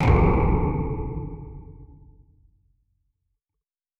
Sci Fi Explosion 24.wav